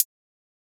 Perc (7).wav